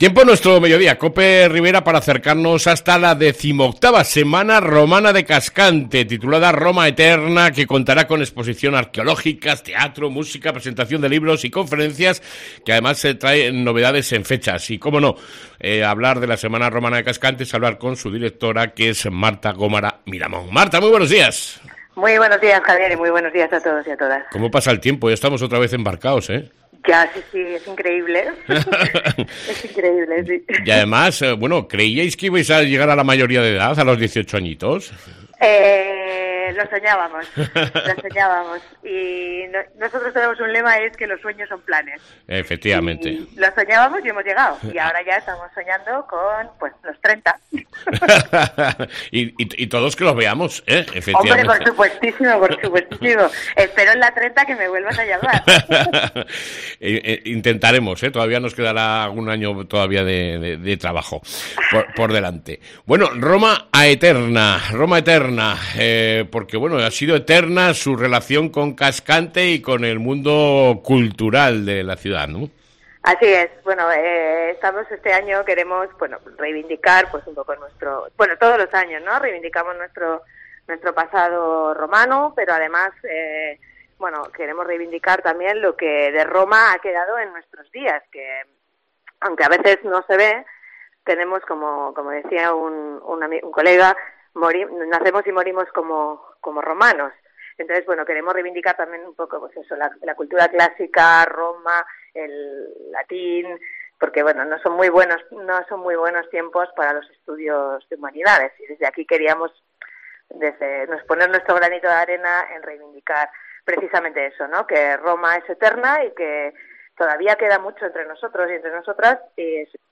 ENTTREVISTA